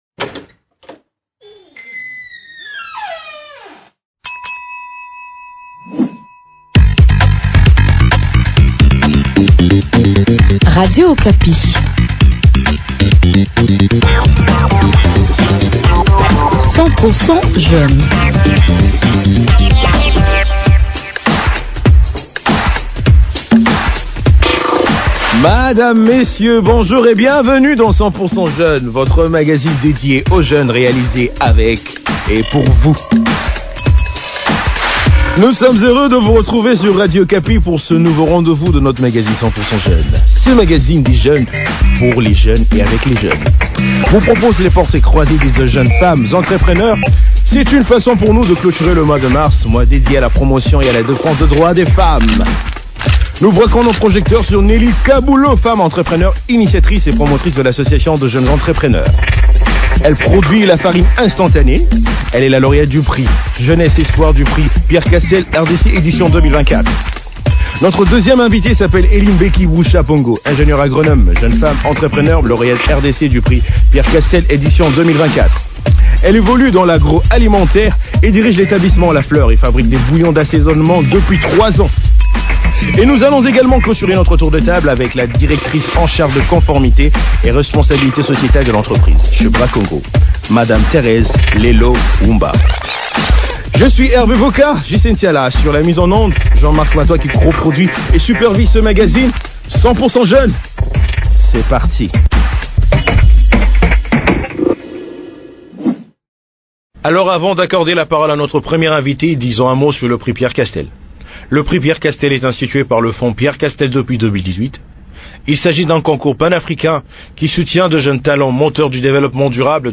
Nous sommes heureux de vous retrouver sur Radio Okapi pour ce nouveau RDV de votre magazine 100% Jeunes sur Radio Okapi. Ce magazine des jeunes pour les jeunes et avec les jeunes vous propose les portraits croisés de deux jeunes femmes entrepreneures c’est une façon pour nous de clôturer le mois de mars, mois dédié à la promotion et à la défense des droits des femmes.